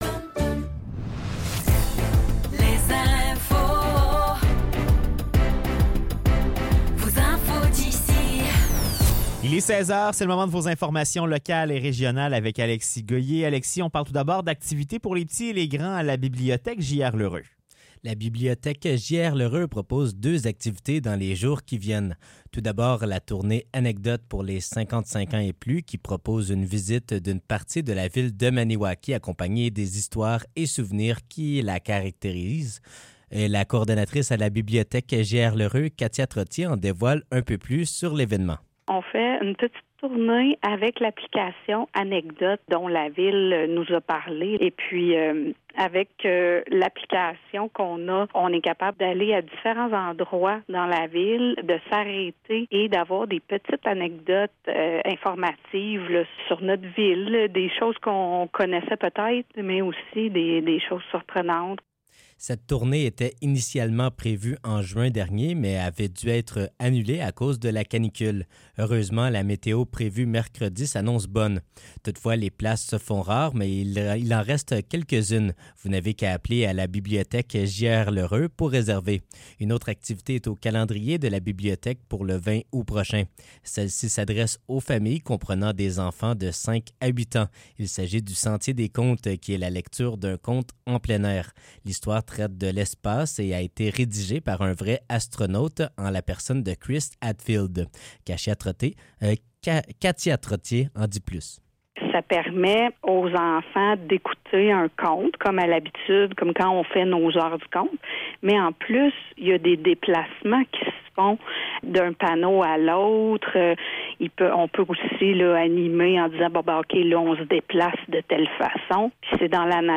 Nouvelles locales - 13 août 2024 - 16 h